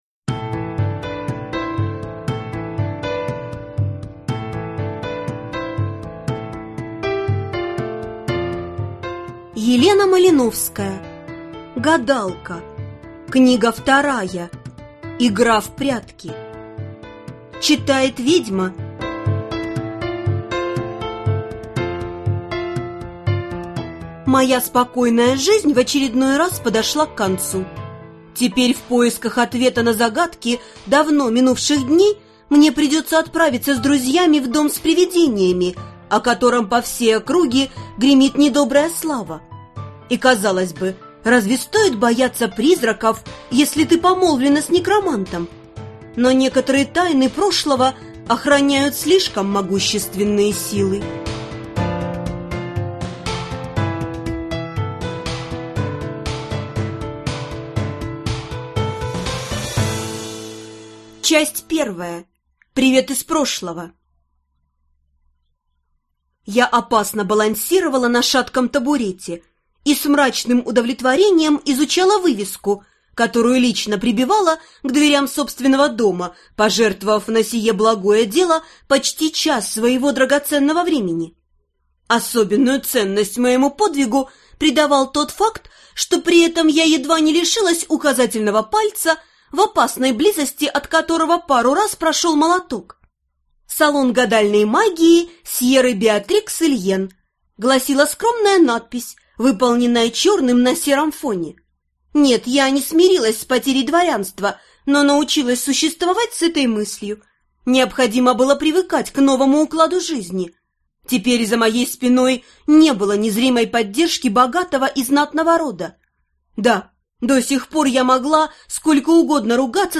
Аудиокнига Игра в прятки | Библиотека аудиокниг